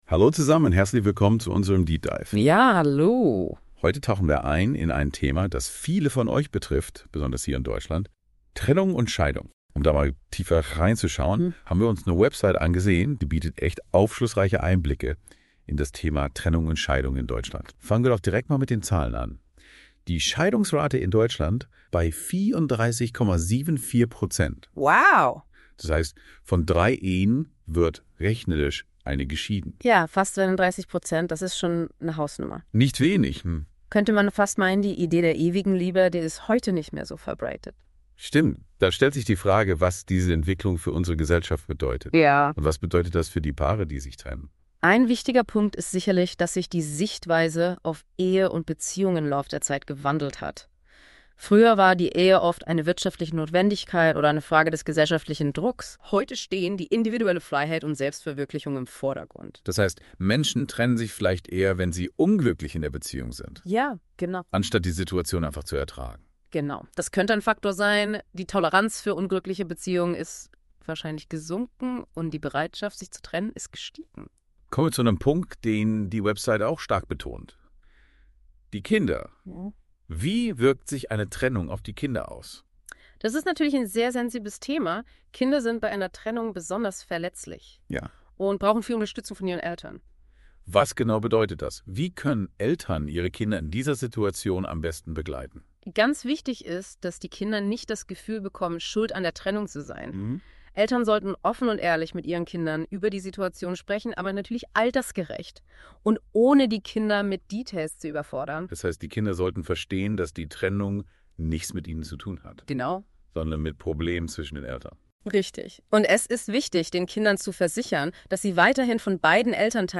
Der Podcast wurde mit KI erstellt, um eine hochwertige und verständliche Wiedergabe zu bieten.